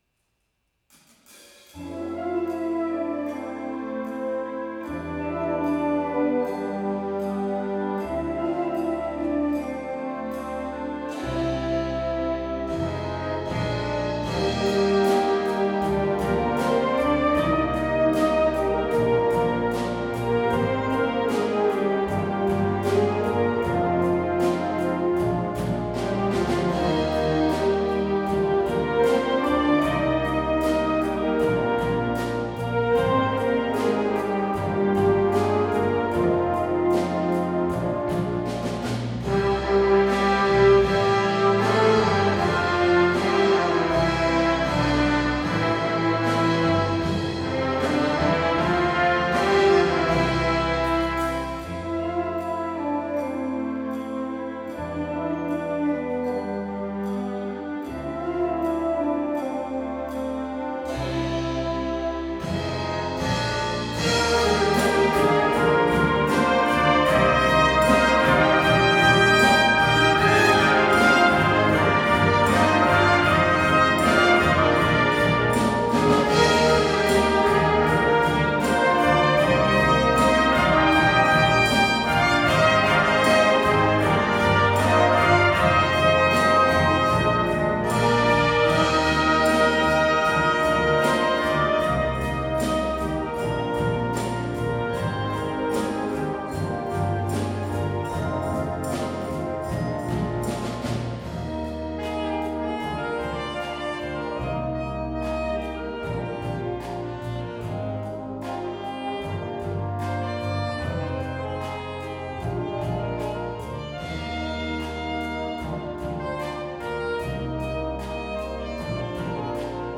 ホールで楽しむ日 12月　三重県文化会館大ホール
とある理由から、なんと三重県文化会館の大ホールで練習できることになりました。
そして、今日の目的といえば、 大好きな曲を録音することです。